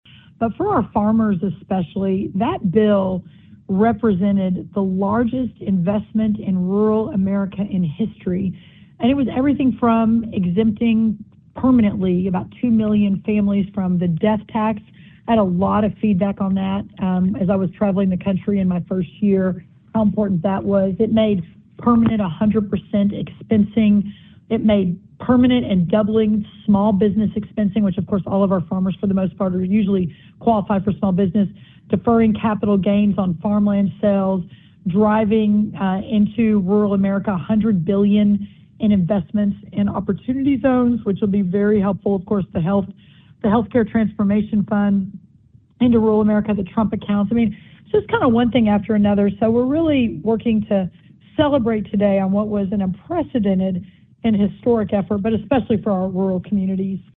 In an interview with the Iowa Agribusiness Radio Network, U.S. Agriculture Secretary Brooke Rollins said several provisions in the law continue to influence decisions tied to succession planning, capital purchases, and rural investment.